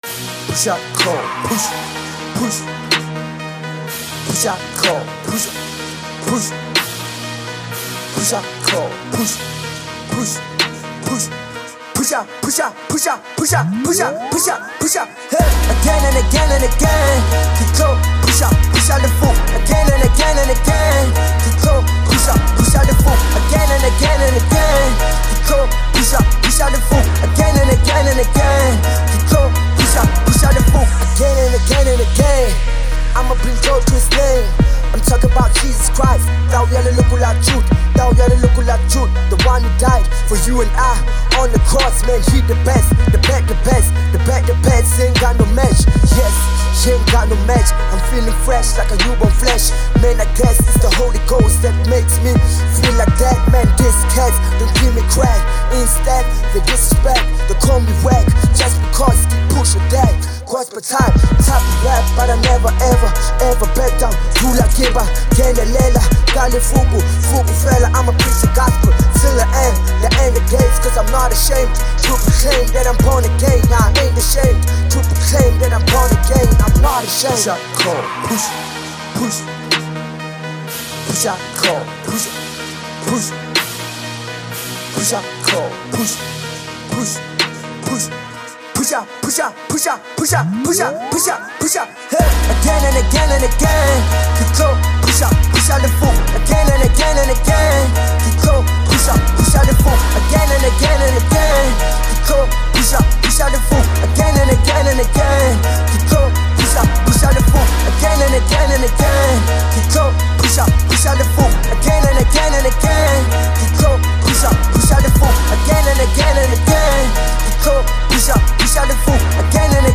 Prolific hip-hop prodigy
Gospel like song